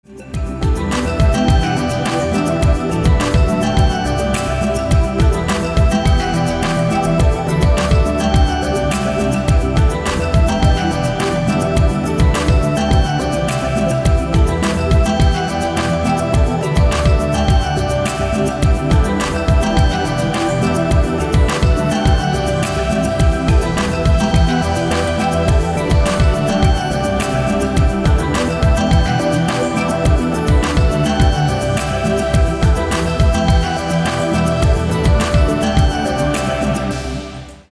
• Качество: 320, Stereo
красивые
dance
электронная музыка
спокойные
без слов
Electronica
chillout
Downtempo